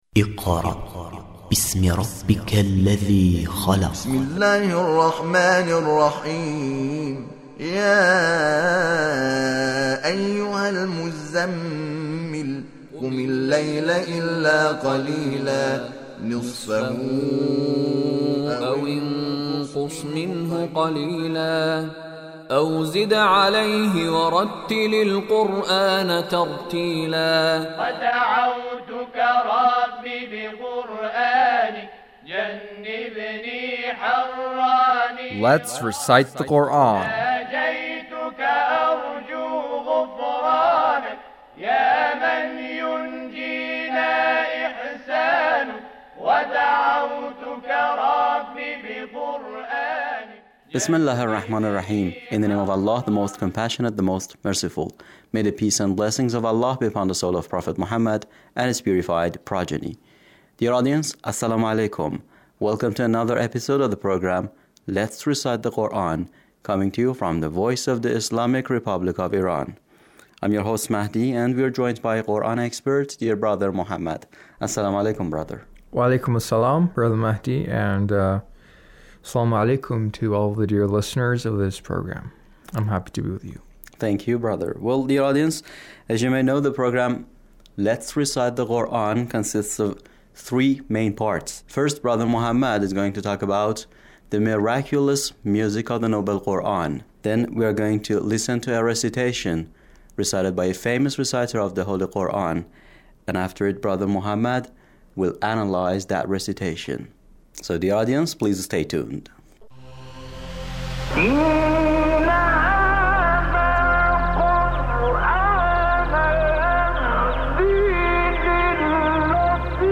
Let's Recite the Quran (808)